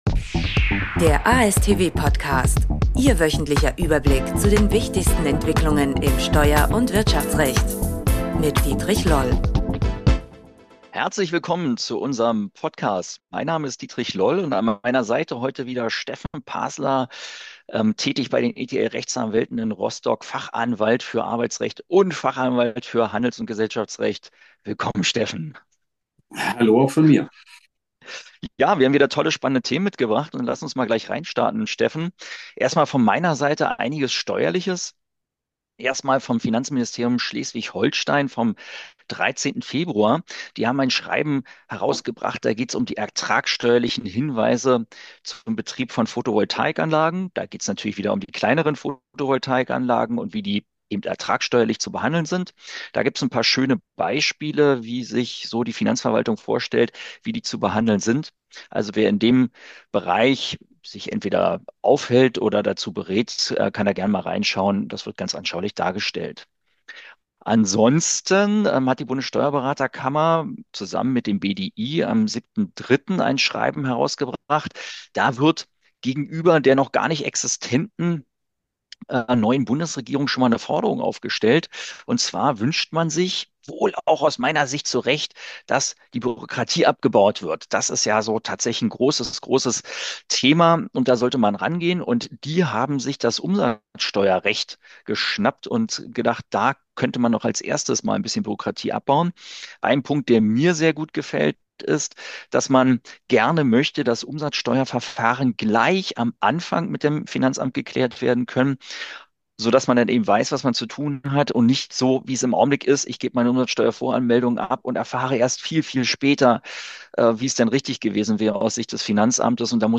Die beiden Experten nehmen die neuesten Entwicklungen im Steuer- und Wirtschaftsrecht unter die Lupe.